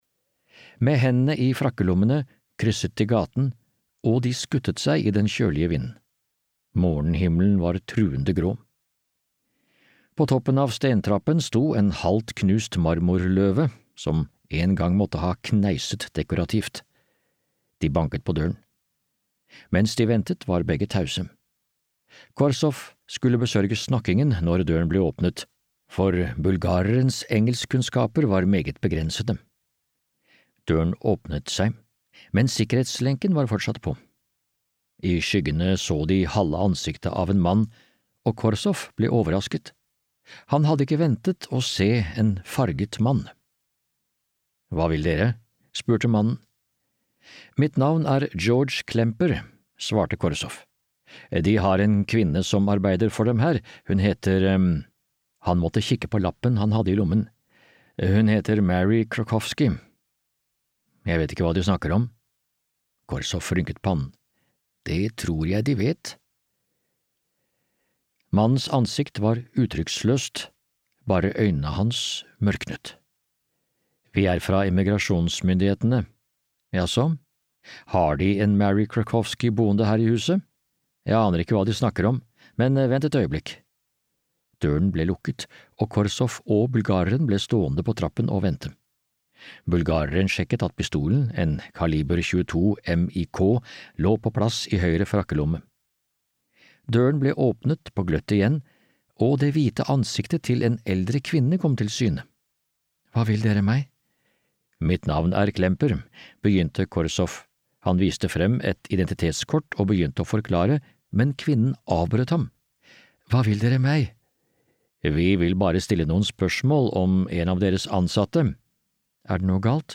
Zürich-koden (lydbok) av Bill Granger